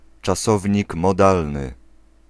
Ääntäminen
Ääntäminen Belgique, Brabant wallon: IPA: [mɔdal] Tuntematon aksentti: IPA: /mo.dal/ Haettu sana löytyi näillä lähdekielillä: ranska Käännös Ääninäyte 1. czasownik modalny {m} 2. modalny {m} Suku: m .